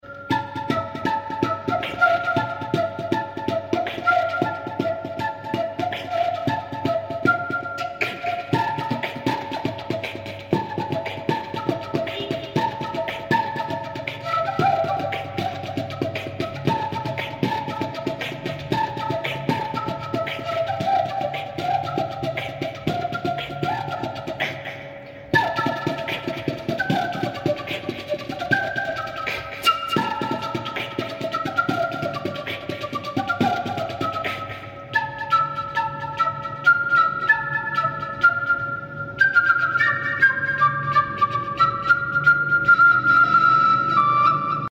flute recorder